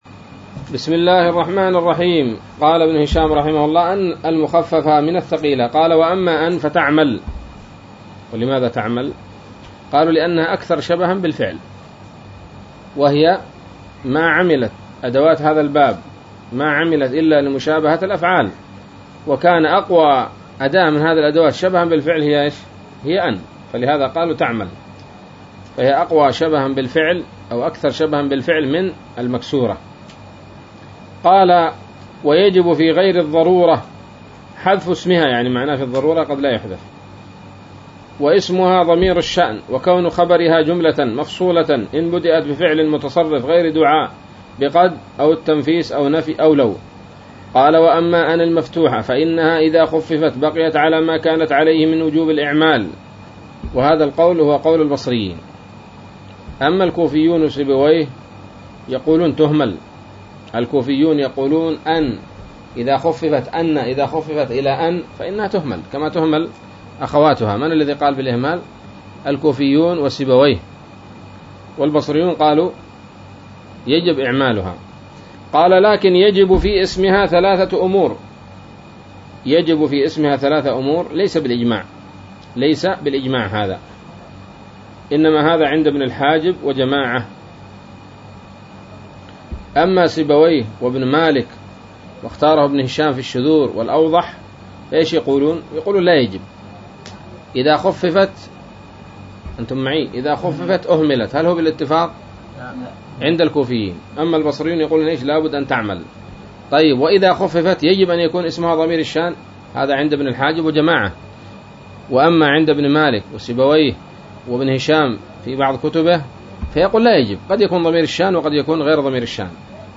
الدرس الرابع والستون من شرح قطر الندى وبل الصدى